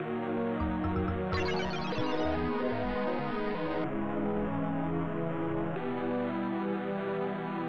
FastTracker Module
Bass Drum Snare Drum Brass (Major) Synthesizer